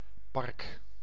Ääntäminen
Synonyymit plantsoen warande Ääntäminen Tuntematon aksentti: IPA: /pɑrk/ Haettu sana löytyi näillä lähdekielillä: hollanti Käännös Ääninäyte Substantiivit 1. park US 2. garden UK US Suku: n .